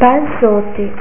(pronuncia)   sfoglia: farina bianca, uova, sale, olio erbe spontanee locali (sènie, alàitalègue), formaggio parmigiano grattugiato, ricotta, uova, olio, sale salsa di noci sui fornelli Ne